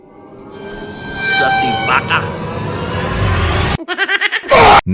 Goofy Ahh Sussy Baka Laugh Jumpscare